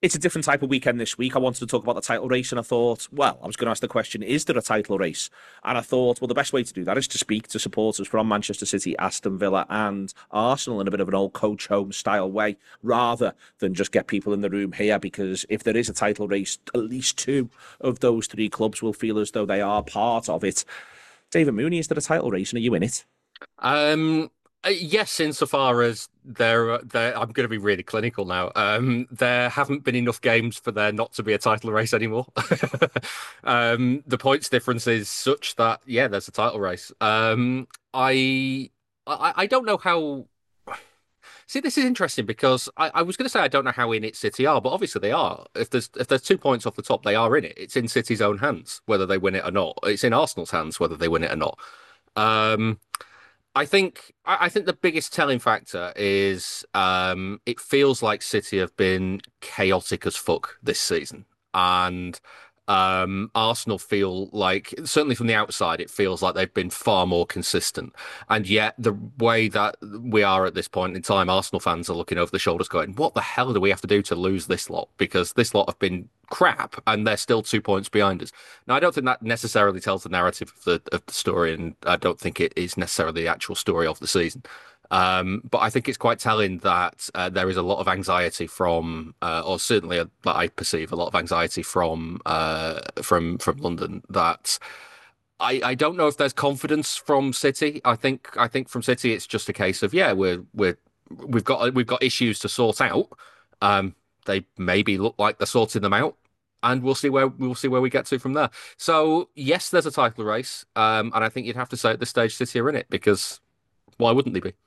Arsenal fan
Aston Villa fan
Manchester City fan